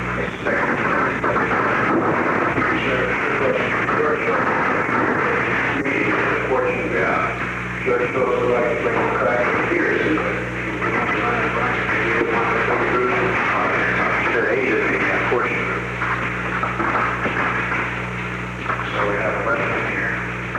On February 19, 1971, President Richard M. Nixon and Alexander P. Butterfield met in the Oval Office of the White House at an unknown time between 9:03 am and 9:05 am. The Oval Office taping system captured this recording, which is known as Conversation 452-001 of the White House Tapes.